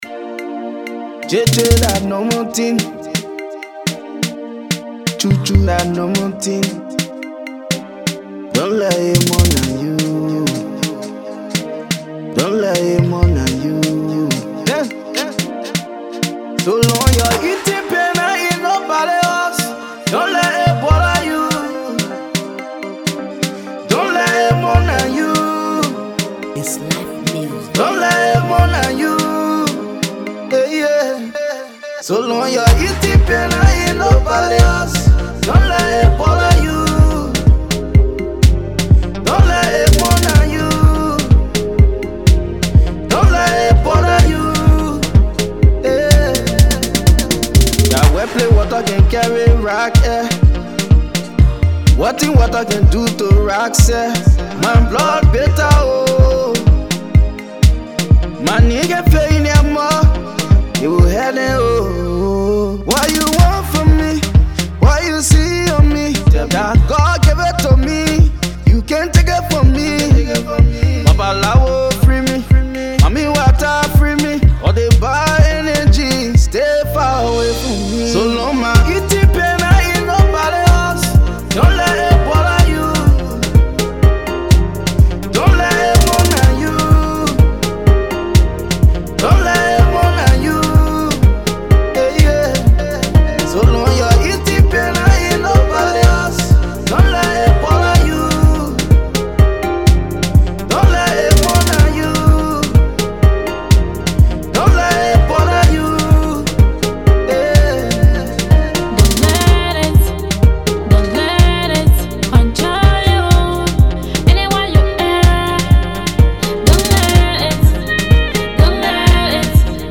/ Afro-Pop, Afrobeats, Colloquial / By